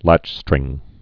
(lăchstrĭng)